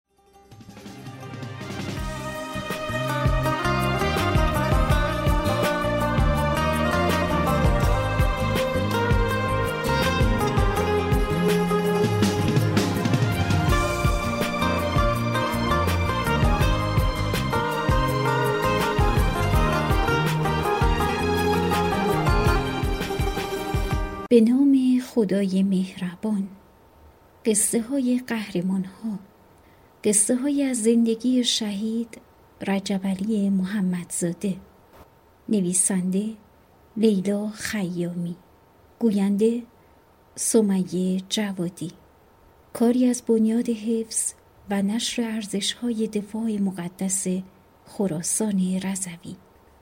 معرفی کتاب صوتی«تخت گاز برو»